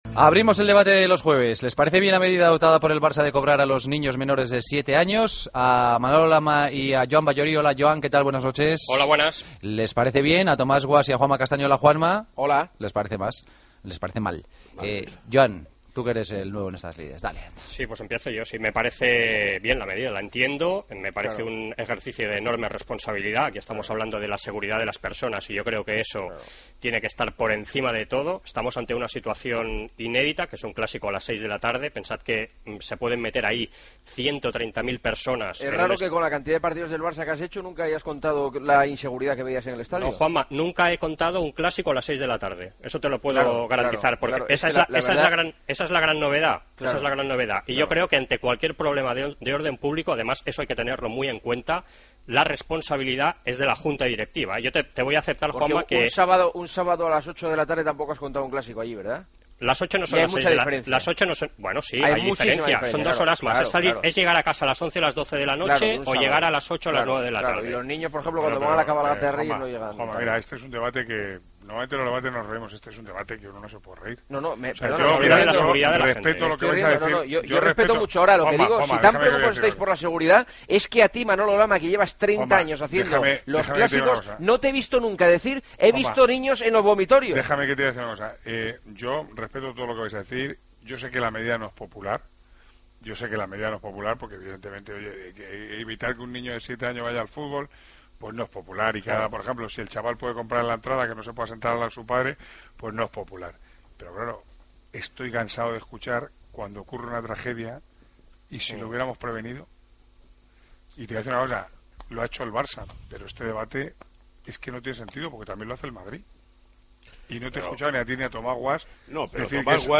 El debate de los jueves: ¿Os parece bien la medida del Barça de querer cobrar a los niños menores de 7 años?